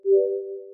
count-down-start.mp3